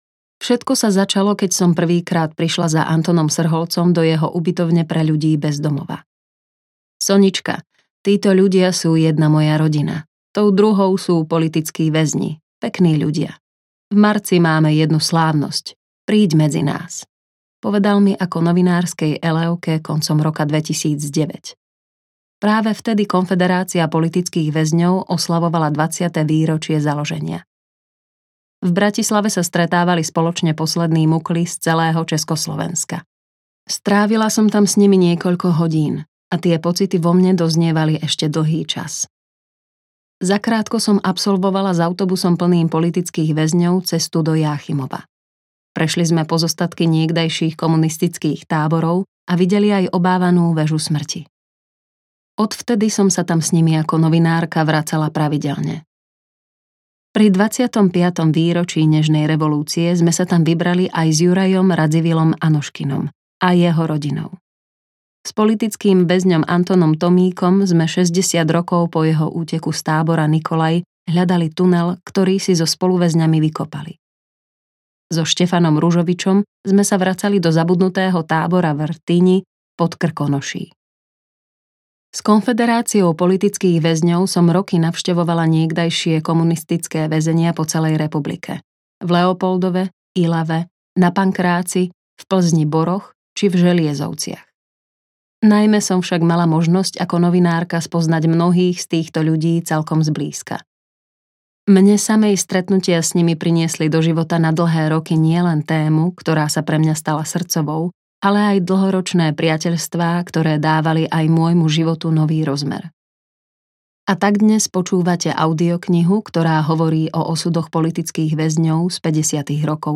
Nenechali sa zlomiť audiokniha
Ukázka z knihy
• InterpretEva Sakálová